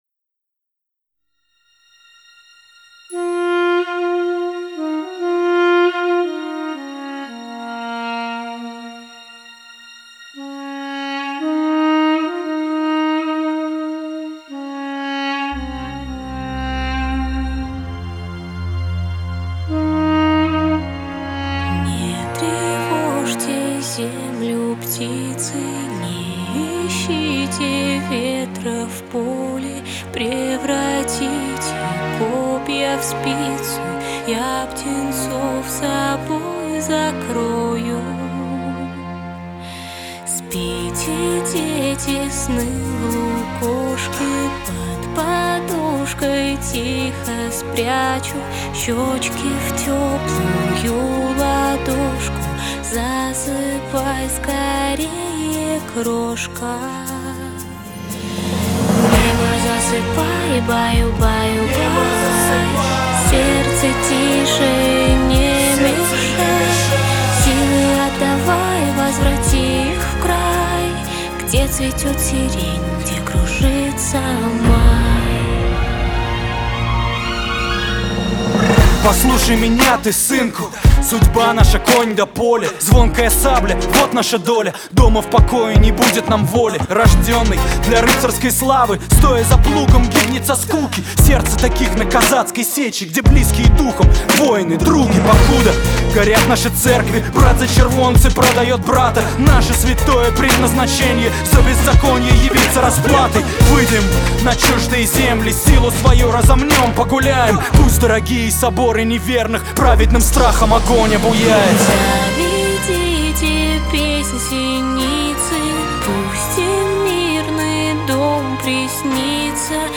Главная » Файлы » Музыка » РэпЧина